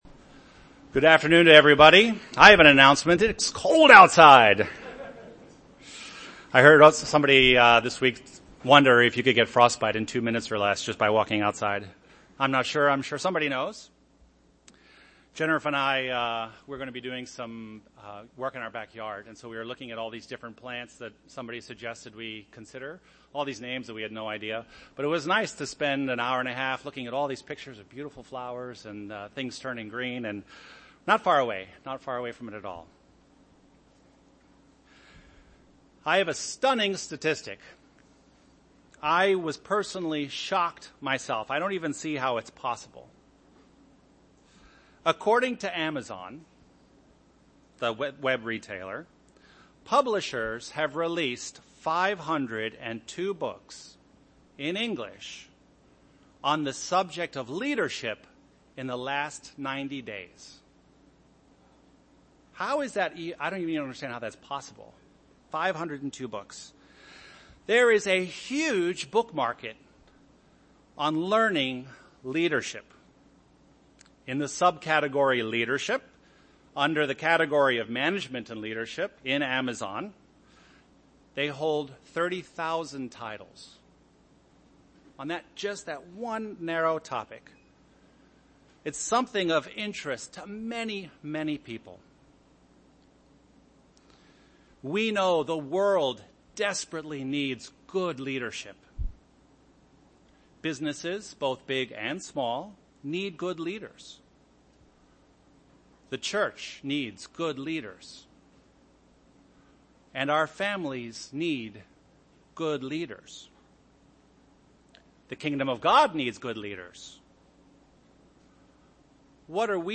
Given in Chicago, IL Beloit, WI
Discover six acts of shepardly leadership UCG Sermon Studying the bible?